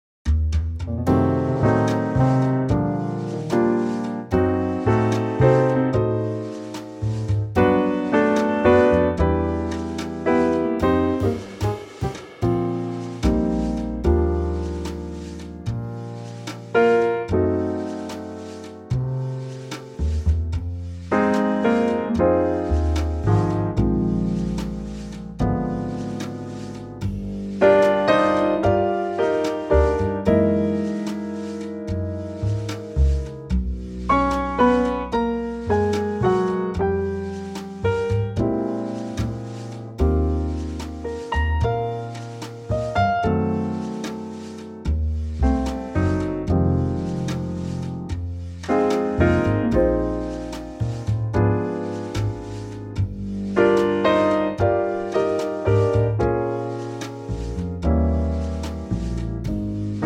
Unique Backing Tracks
key - Eb - vocal range - C to C
Absolutely gorgeous song in a 2025 Trio arrangement.